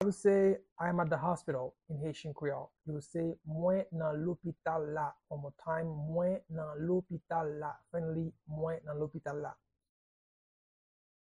Pronunciation and Transcript:
How-to-say-I-am-at-the-hospital-in-Haitian-Creole-–-Mwen-nan-lopital-la-pronunciation-by-a-native-Haitian.mp3